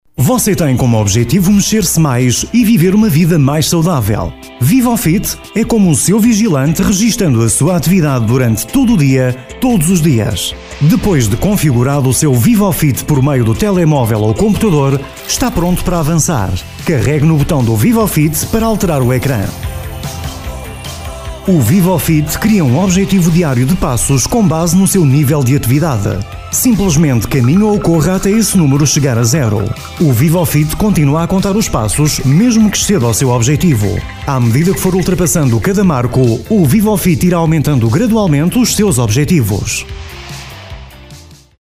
Sprechprobe: Sonstiges (Muttersprache):
His voice has been described as warm, smooth, sophisticated, natural and youthful.